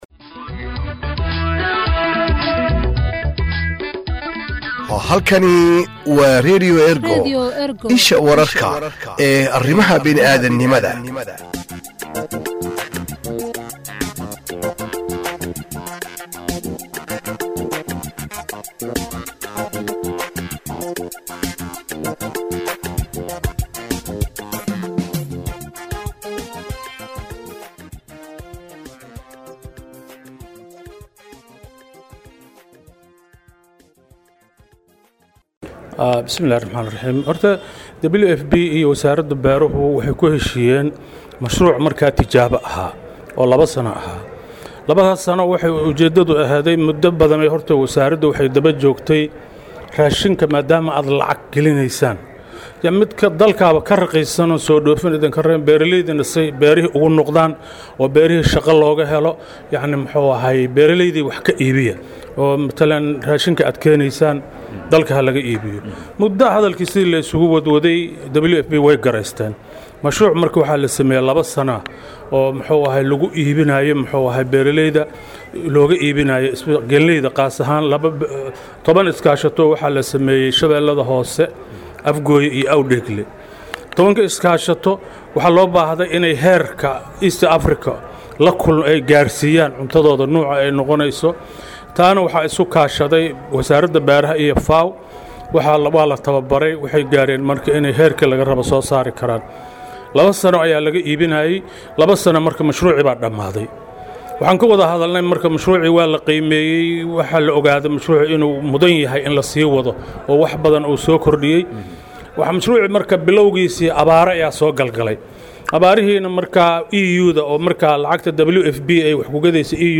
Wareysi: WFP oo ballaarineysa iibsiga wax soo saarka beeraleyda Soomaalida